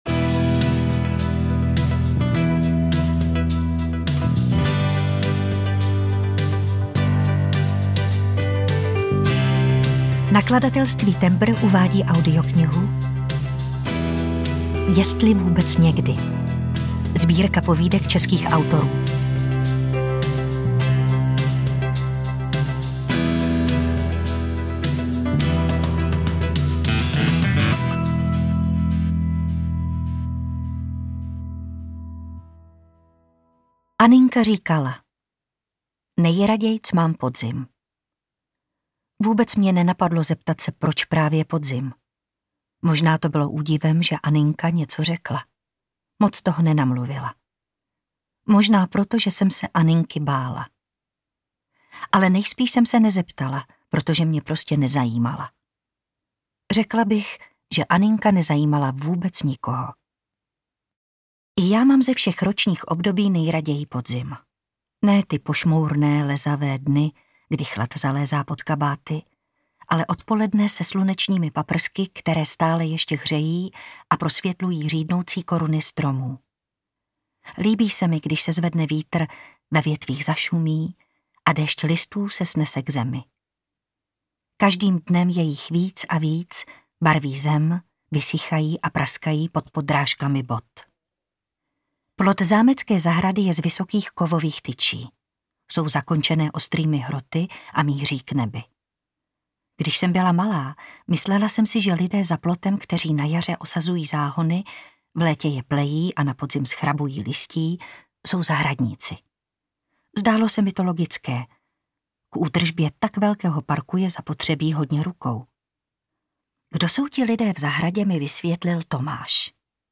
Jestli vůbec někdy audiokniha
Ukázka z knihy